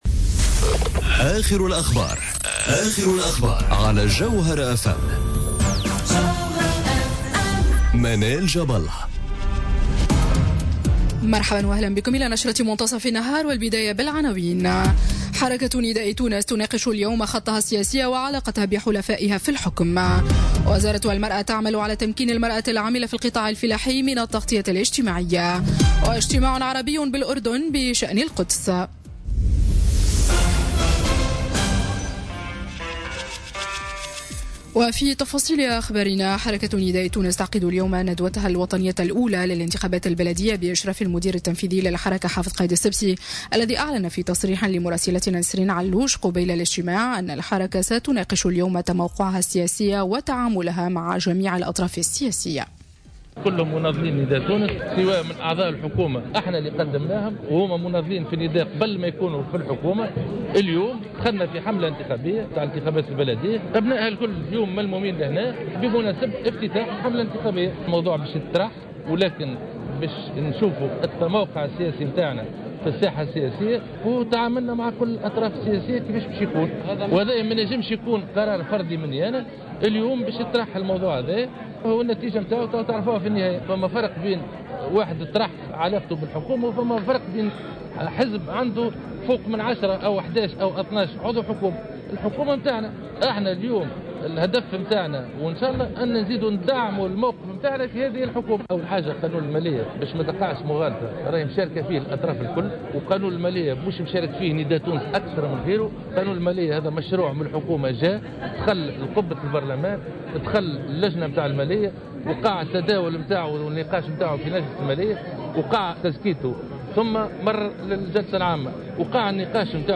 نشرة أخبار منتصف النهار ليوم السبت 6 جانفي 2018